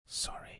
Download Whisper sound effect for free.
Whisper